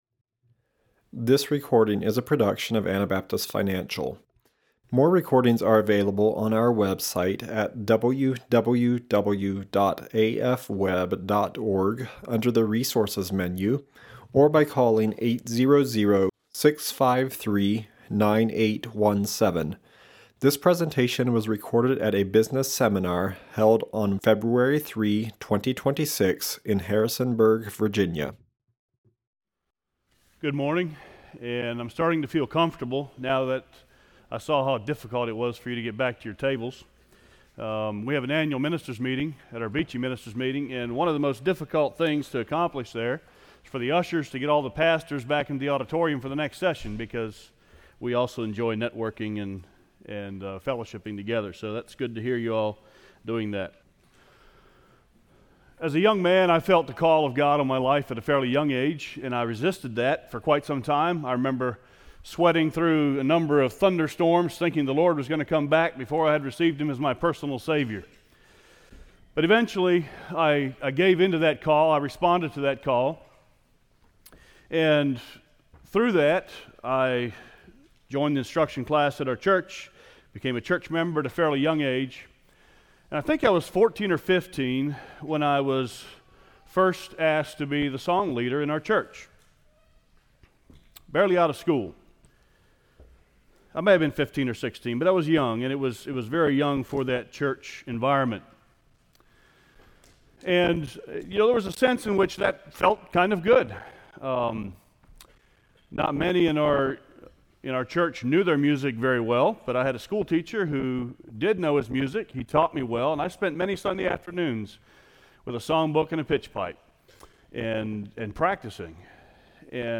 Virginia Business Seminar 2026